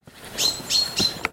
bird short